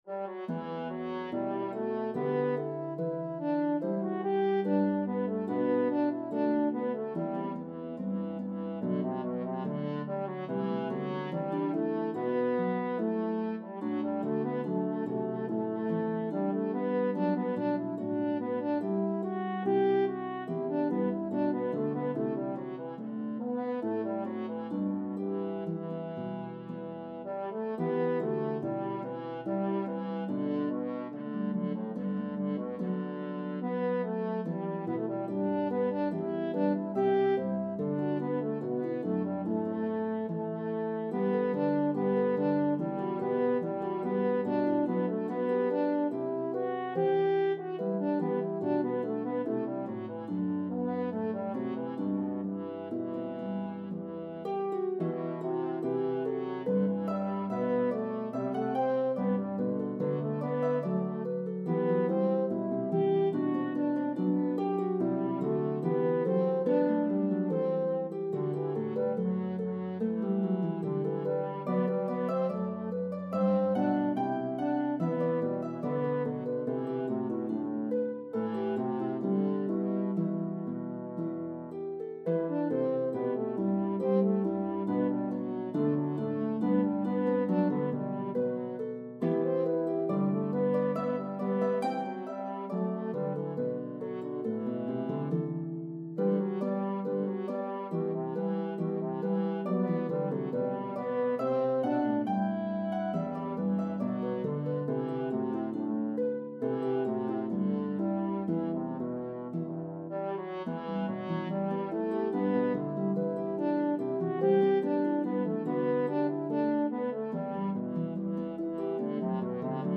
This beautiful Aeolian melody has a debated history.